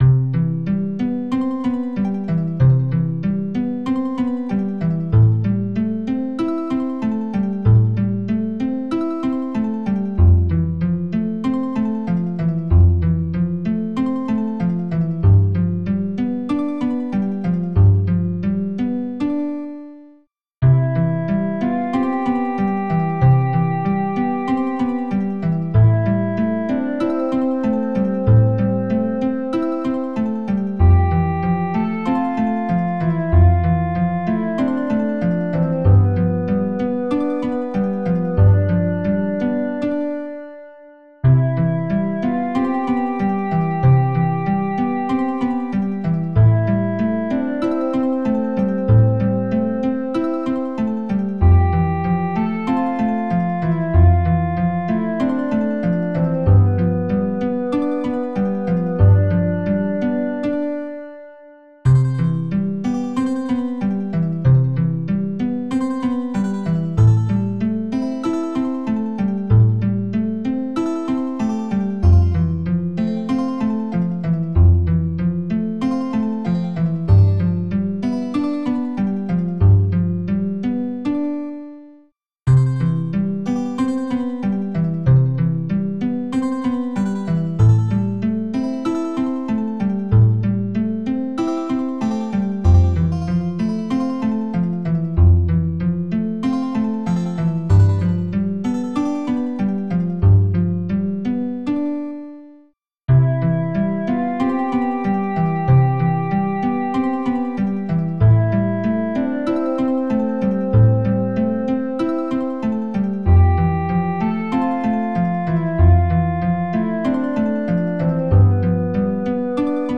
This is a piece that might play in an rpg or adventure game, while your characters explore a village, or maybe during a start screen. It is compsed using actual sounds from the SNES, so it would be perfect for a 16-bit game. It can be looped endlessly.